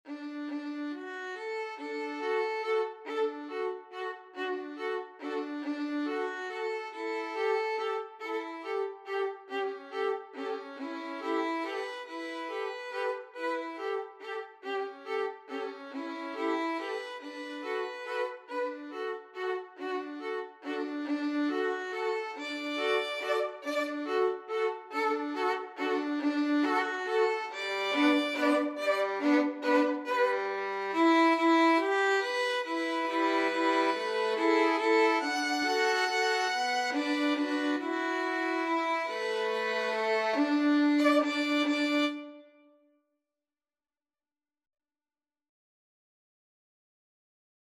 Free Sheet music for Violin Duet
Violin 1Violin 2
D major (Sounding Pitch) (View more D major Music for Violin Duet )
=140 Slow one in a bar
3/4 (View more 3/4 Music)
Classical (View more Classical Violin Duet Music)